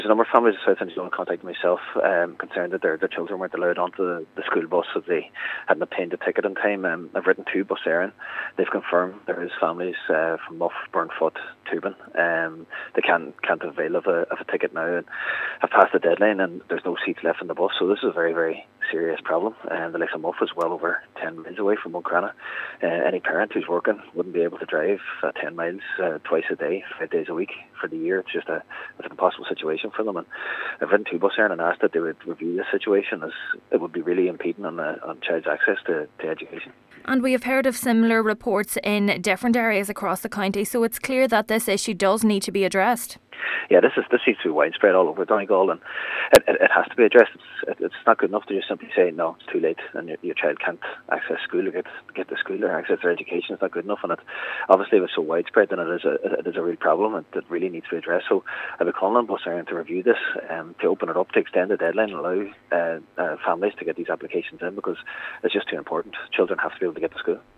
Councillor Murray says this is a widespread issue that must be addressed without delay: